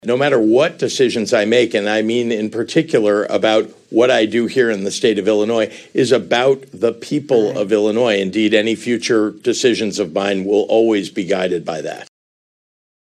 (Springfield, IL)  —  Governor Pritzker isn’t ruling out a 2028 presidential run.  Pritzker was asked about a potential run while appearing on NBC’s “Meet the Press” with Kristen Welker.